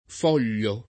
foglio [ f 0 l’l’o ]